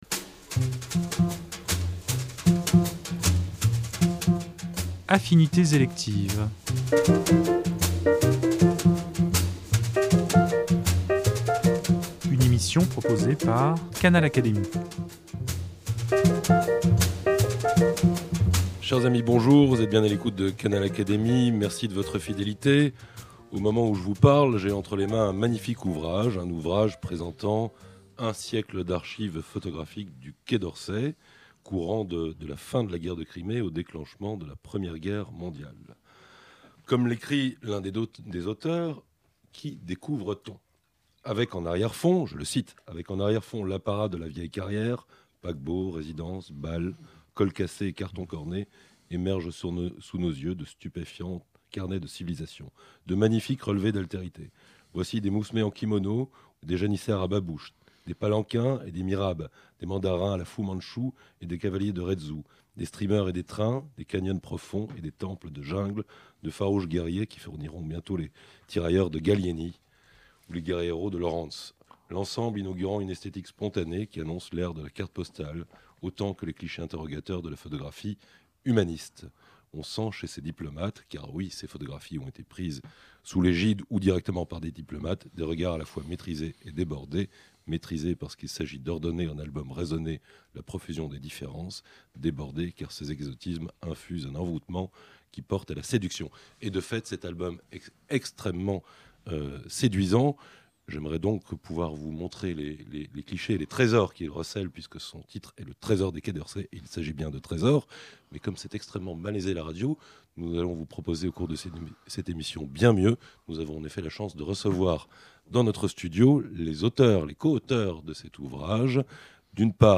Comme en témoigne l’entretien foisonnant qu’ils nous ont accordé, ces photographies ont le don de stimuler la réflexion et l’imagination. Au fil de cette émission, il est en effet tour à tour question de diplomatie et de photographie, mais aussi d’histoire, de géopolitique, d’ethnologie, de géographie, de philosophie et de littérature !